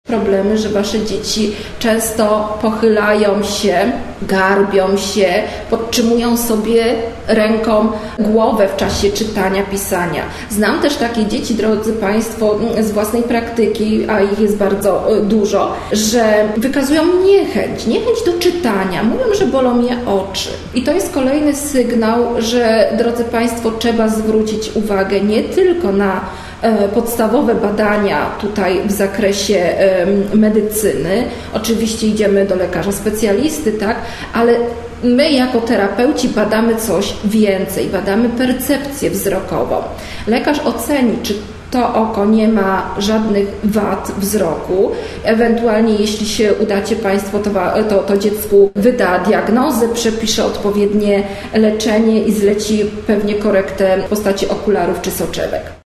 Sokolniki: Kolejny wykład w ramach budżetu obywatelskiego „Łódzkie na Plus”
Wszystkie wykłady są transmitowane na żywo na facebooku Gminnego Ośrodka Kultury Sportu i Turystyki w Sokolnikach.